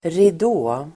Uttal: [rid'å:]